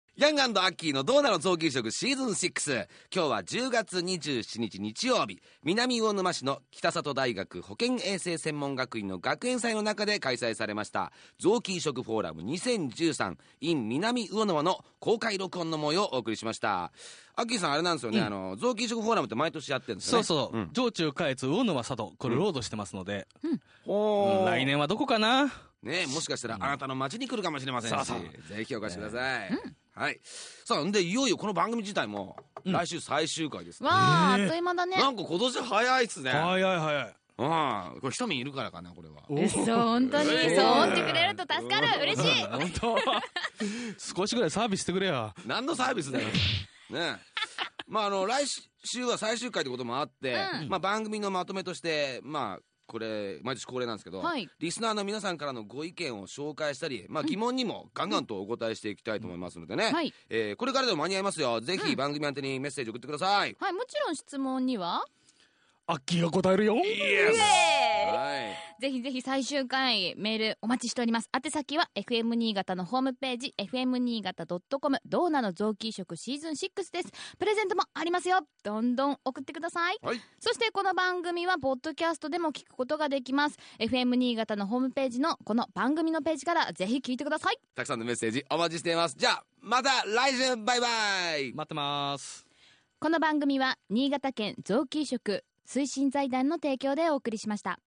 2013年10月27日に南魚沼市の北里大学 保健衛生学院で行われた「臓器移植フォーラム2013in南魚沼」での番組公開録音。
※BGMやリクエスト曲、CMはカットしています。